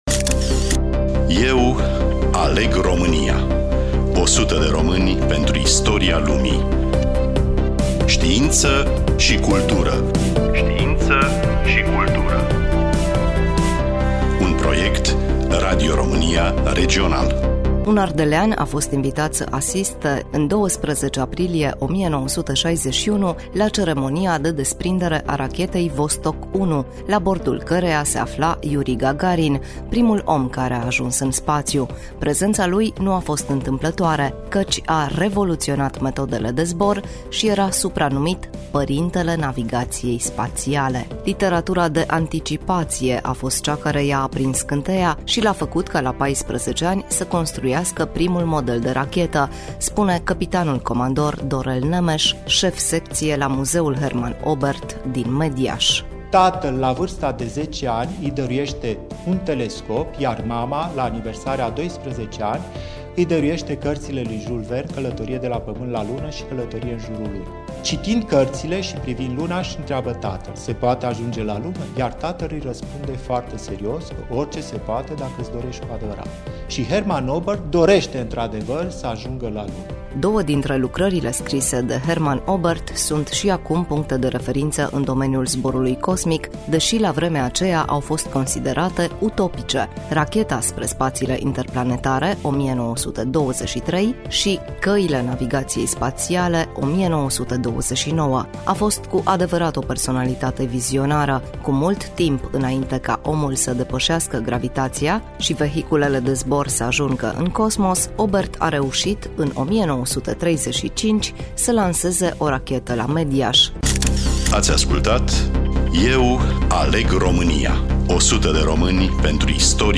Studioul: Radio România Cluj